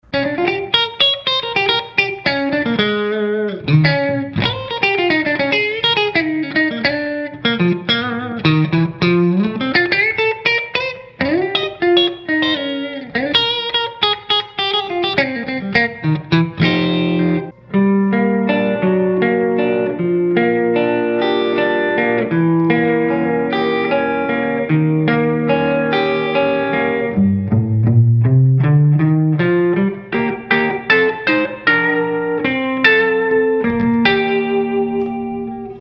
O Falante Crazy Diamond da BGT SPEAKER possui médios controlados e suaves, graves bem presentes e firmes, médios agudos cristalino e não ardido.
CLEAN
crazy_diamond_clean.mp3